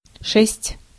Ääntäminen
France (Paris): IPA: /sis/